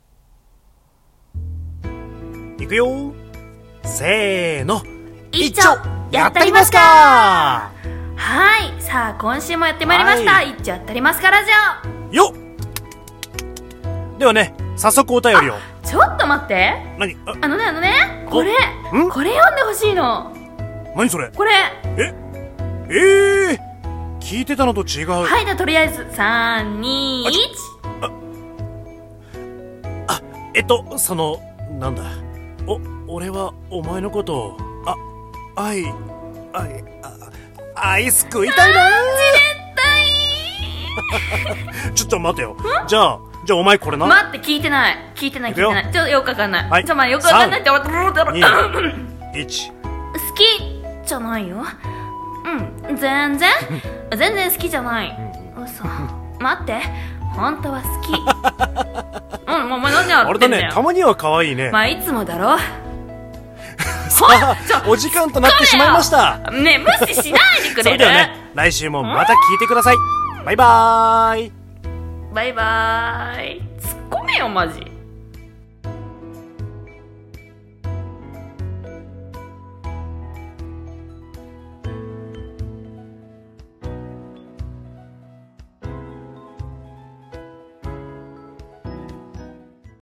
掛け合い声劇